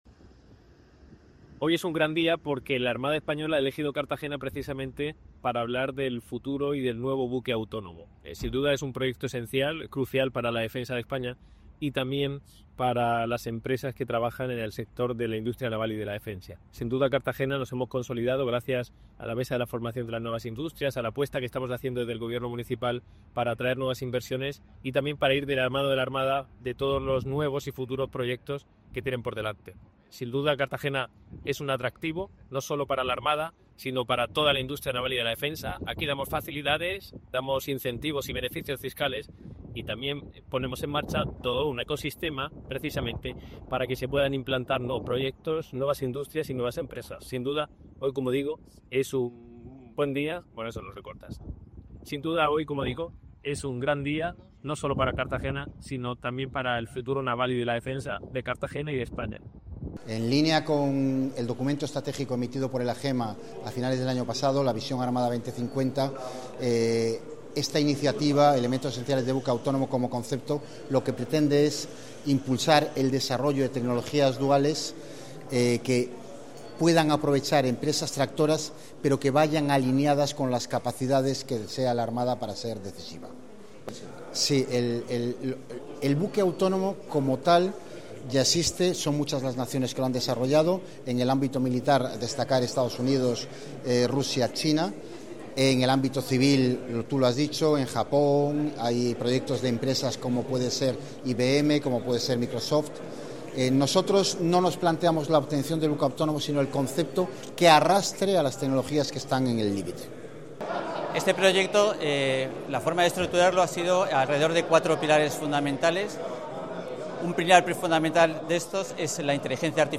La Escuela de Infantería de Marina ‘General Albacete y Fuster’ ha sido el lugar elegido para la presentación, este jueves 11 de diciembre, de la Fase 1 del Proyecto Elementos Esenciales del Buque Autónomo, un encuentro estratégico donde industria, instituciones y agentes innovadores ha podido conocer los avances que marcarán el futuro en el ámbito de las tecnologías duales y la defensa.